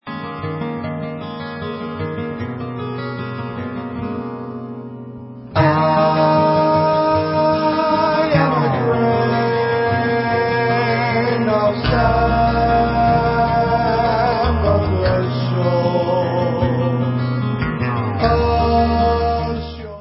PROG. THRASH METAL